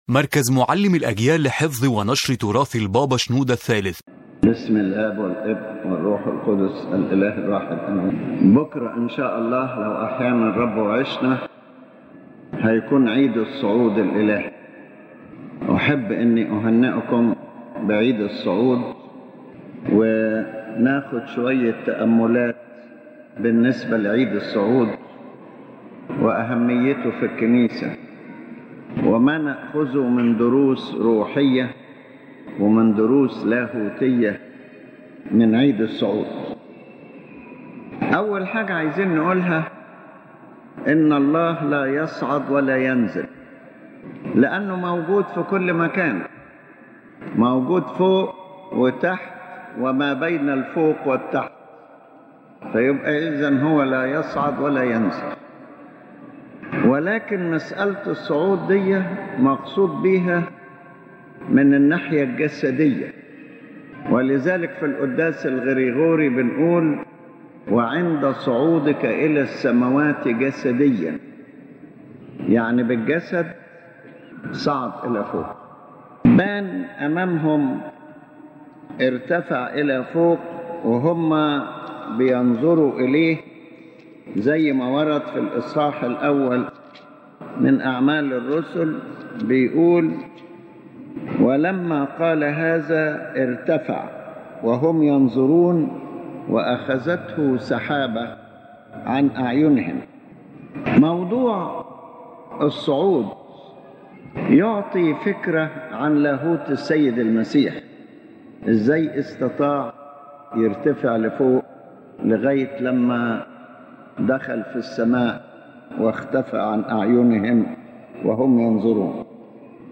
The Main Idea of the Lecture